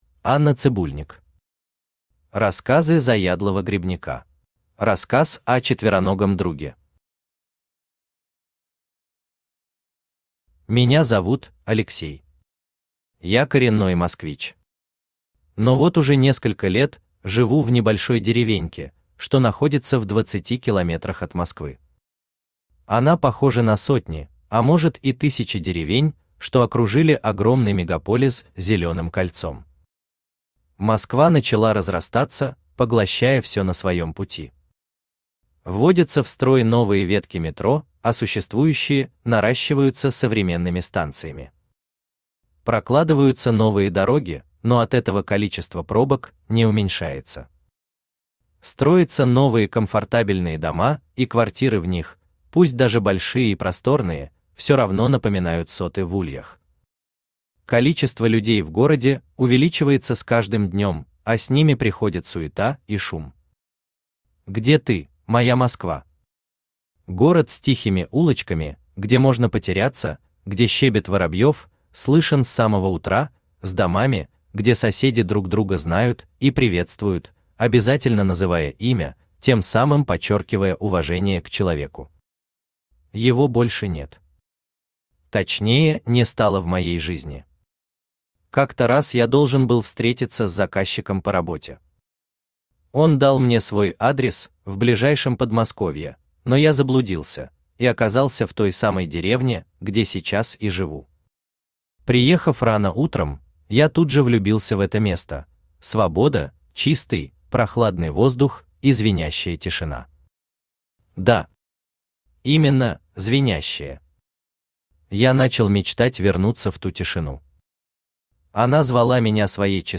Аудиокнига Рассказы заядлого грибника | Библиотека аудиокниг
Aудиокнига Рассказы заядлого грибника Автор Анна Николаевна Цыбульник Читает аудиокнигу Авточтец ЛитРес.